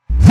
Reverse Kick OS 01.wav